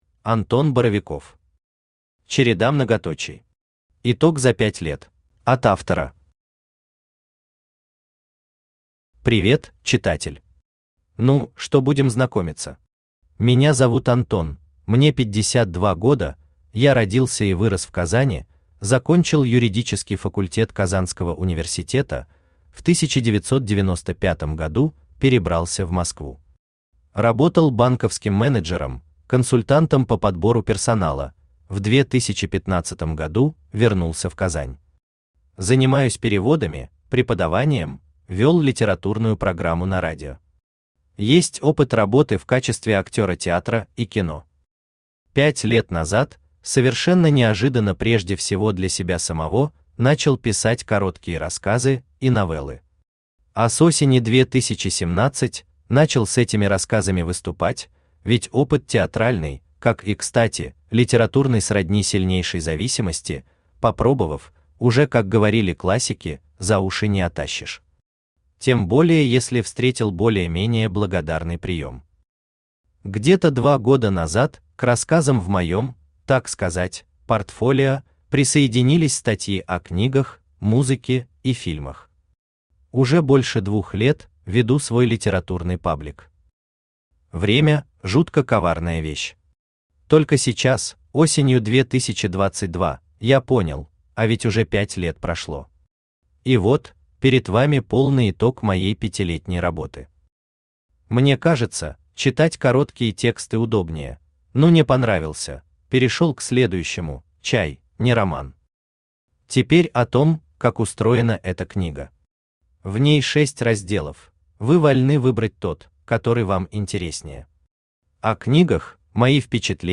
Аудиокнига Череда многоточий. Итог за 5 лет | Библиотека аудиокниг
Итог за 5 лет Автор Антон Боровиков Читает аудиокнигу Авточтец ЛитРес.